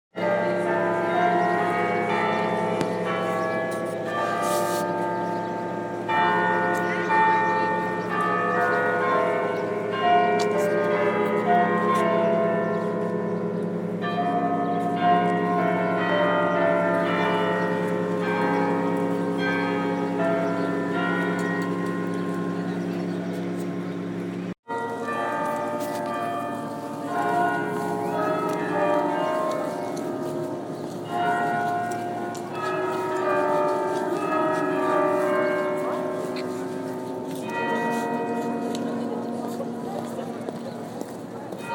St Patks NY Bells